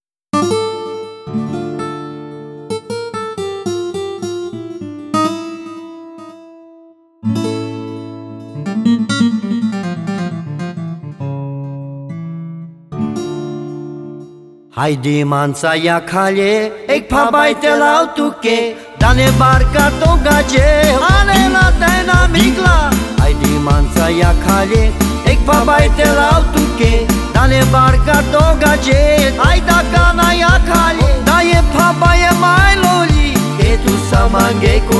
Жанр: Кантри
# Contemporary Country